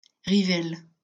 Rivel (French pronunciation: [ʁivɛl]